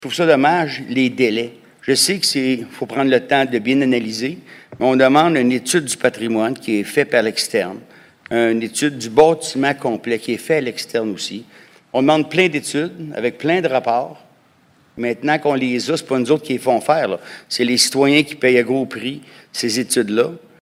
M. Lemire se trouvait à émettre ce commentaire lors du conseil d’arrondissement Saint-Hubert cette semaine.